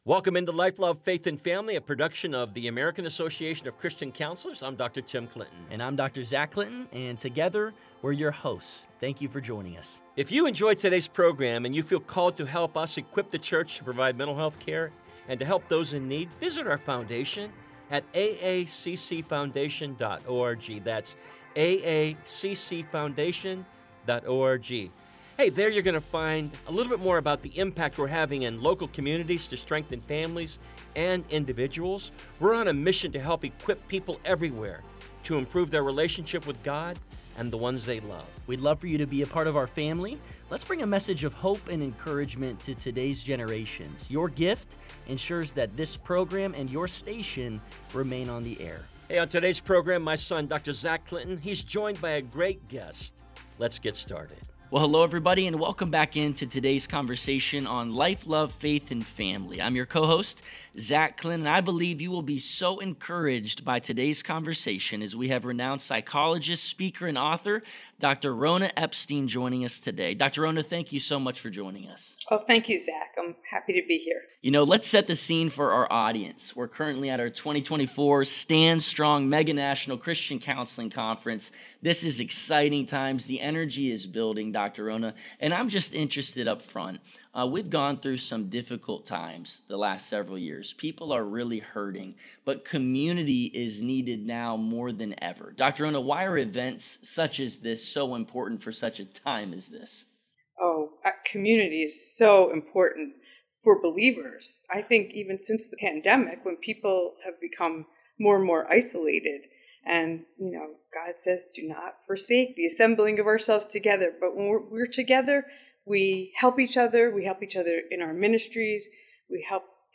Backstage at an Extraordinary Women event in Tulsa